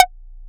edm-perc-15.wav